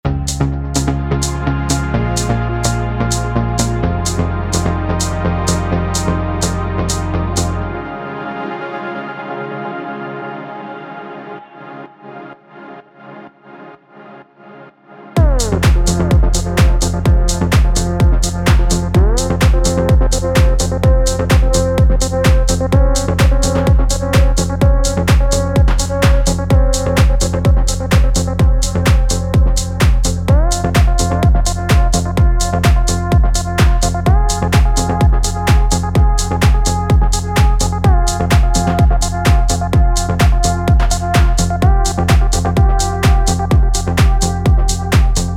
Liite_4_Case_2_Hanoi_Manual Master.mp3